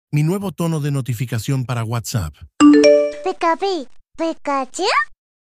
Tono de notificación para WhatsApp sound effects free download
Tono de notificación para WhatsApp- pikachu